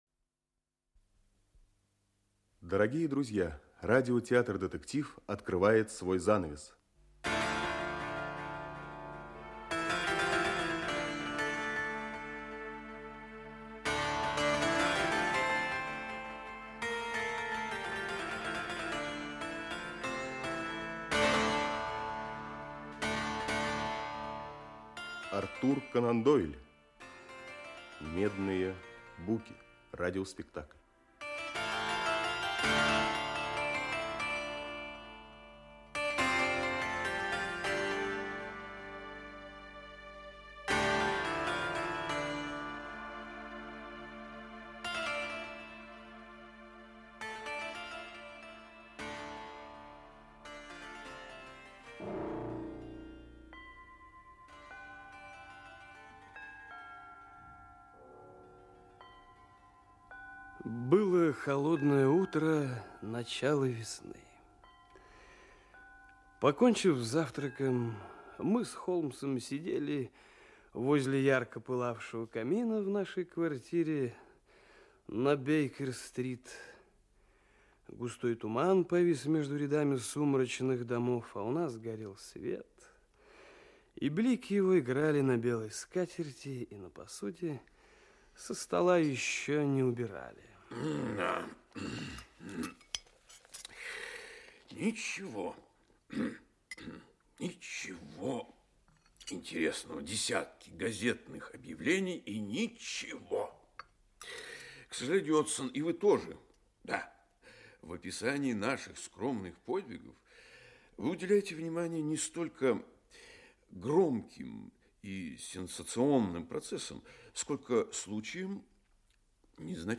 Аудиокнига Медные буки. Часть 1 | Библиотека аудиокниг
Часть 1 Автор Артур Конан Дойл Читает аудиокнигу Актерский коллектив.